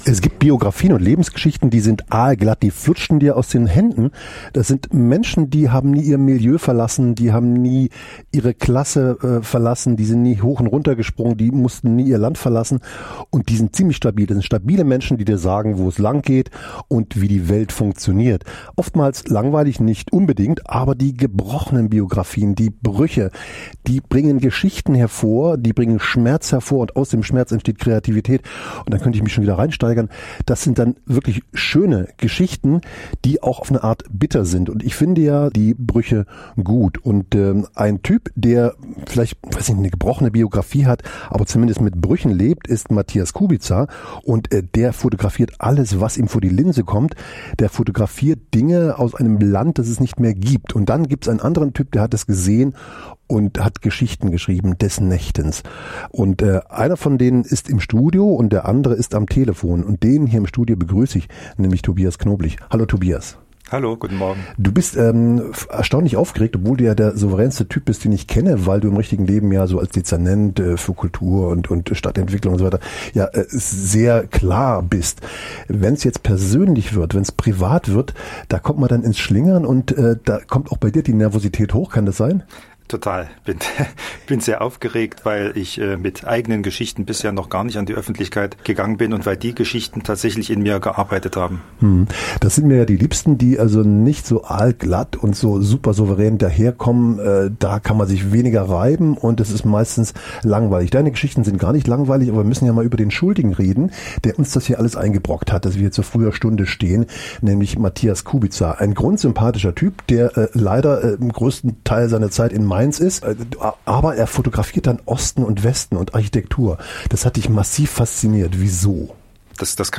September 2023 - 19:30 Uhr | Haus Dacheröden - Sommerbühne Open Air: Zwei Generationen erkunden Ostdeutschland.